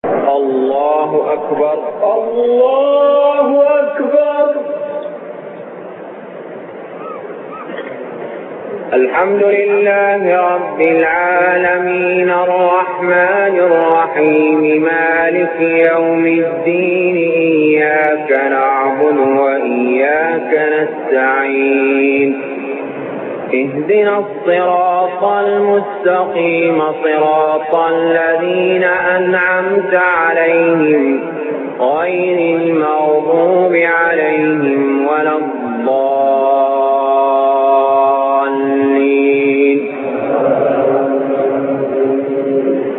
المكان: المسجد الحرام الشيخ: علي جابر رحمه الله علي جابر رحمه الله الفاتحة